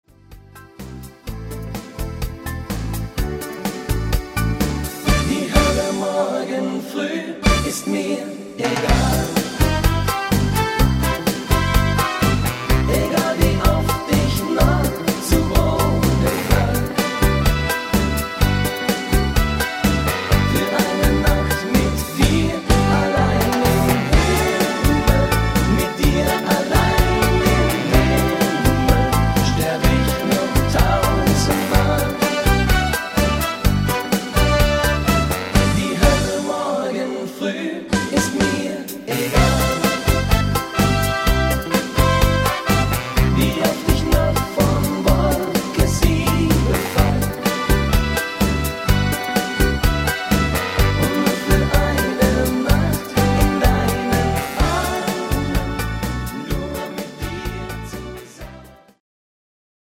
Latin-Männer-Version